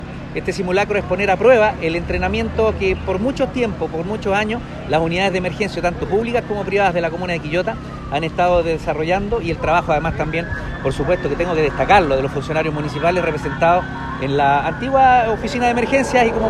Alcalde-Oscar-Calderon-Sanchez-3.mp3